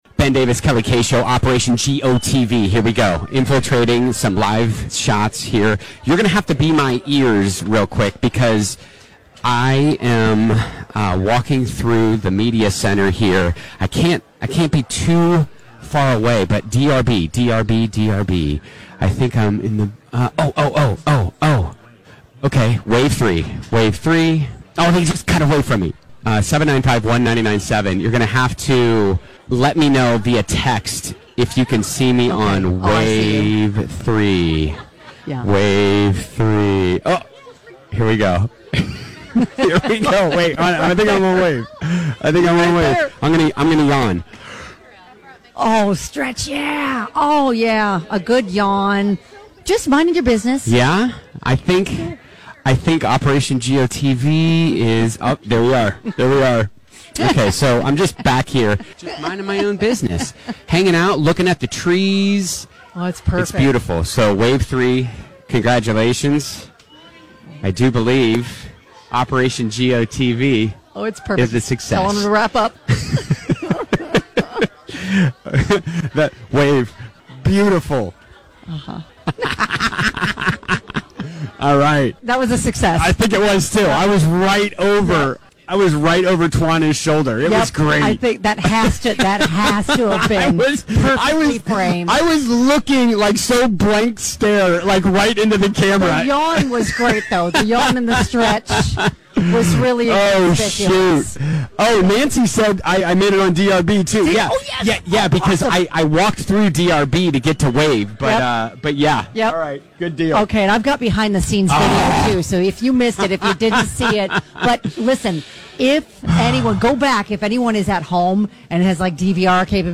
It's a time honored tradition when we are live backside at Churchill Downs